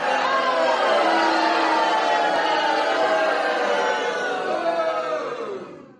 Crowd Cheering
# crowd # cheer # stadium About this sound Crowd Cheering is a free sfx sound effect available for download in MP3 format.
365_crowd_cheering.mp3